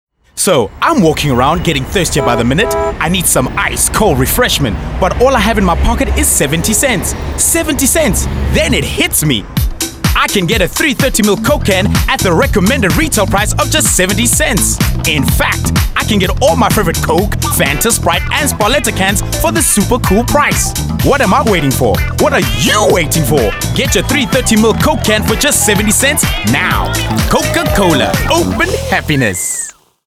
authoritative, mature, seasoned, wise
His delivery varies from bright, conversational, corporate, soft and hard sell, plus he will bring life to any script.
My demo reels